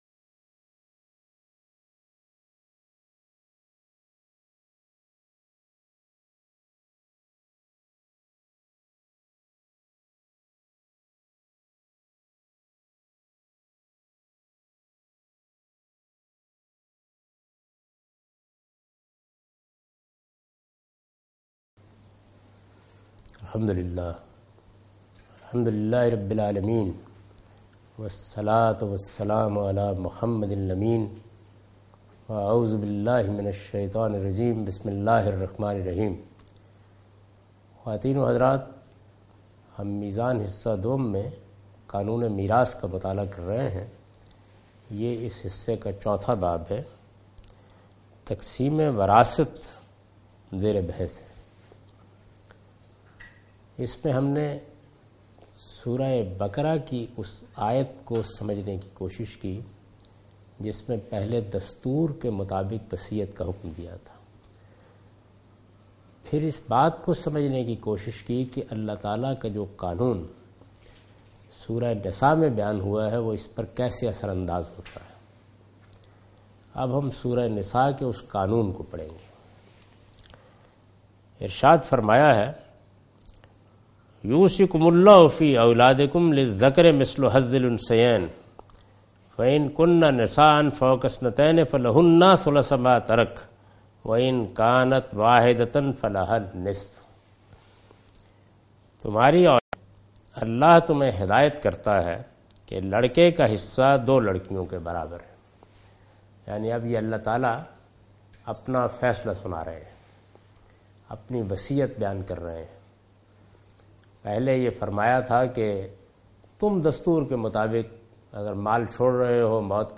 Javed Ahmad Ghamidi teaching from his book Meezan. In this lecture he teaches from the chapter "Qanoon e Maeshat".